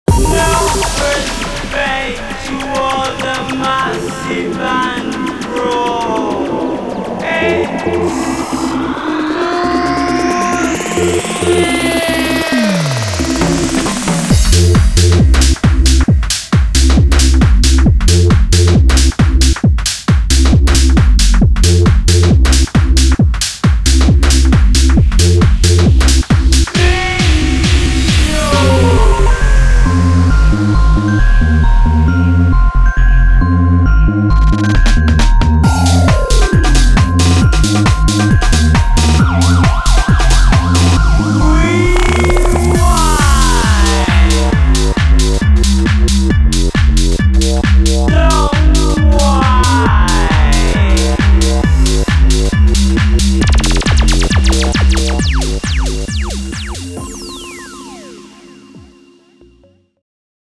Uk Garage